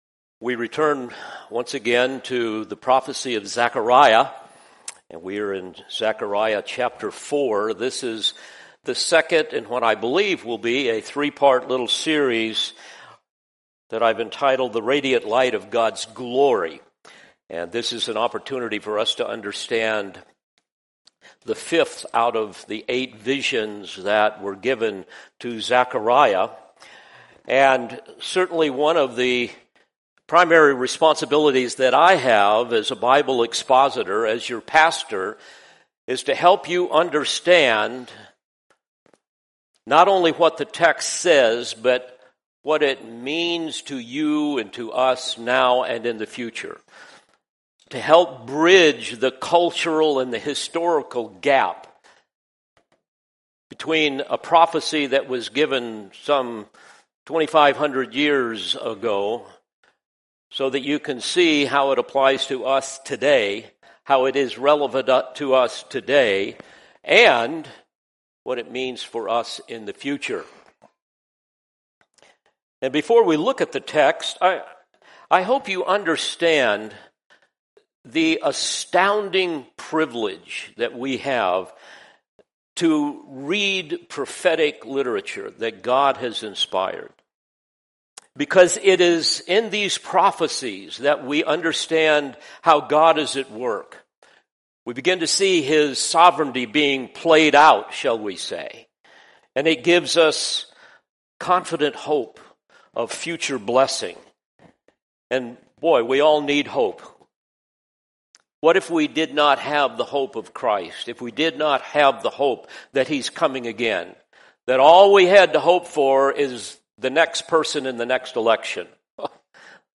In this powerful message, we dive into Acts 2:42-47 and uncover the life-changing secrets behind the early church’s unstoppable faith. From devotion to prayer and teaching to radical community and shared service, the early believers set the bar for what true Christian life looks like.